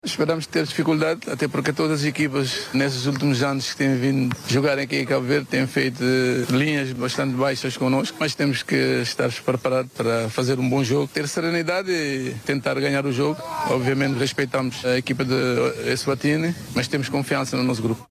Declarações Pedro Leitão Bobista selecionar Nacional al de Cabo Verde e a sua crença no apuramento da seleção de Cabo Verde, diante da similar de Eswatinni na rota ao mundial.